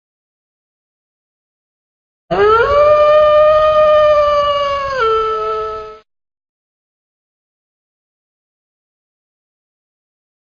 AWOO-Wolf-Howling-Sound-Effect.mp3
KGuk6kGxEJY_AWOO-Wolf-Howling-Sound-Effect.mp3